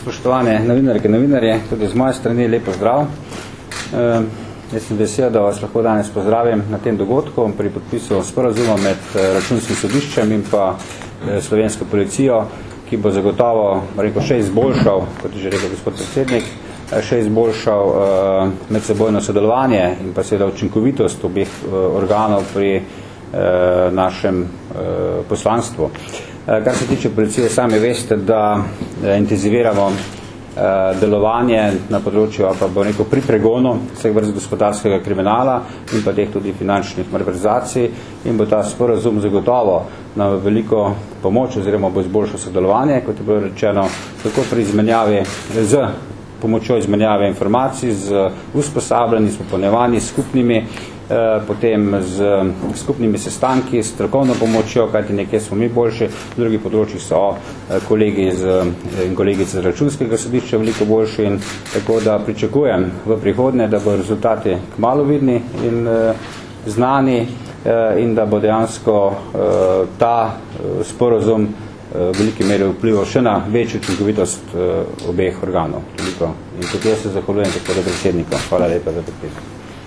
Zvočni posnetek izjave Stanislava Venigerja (mp3)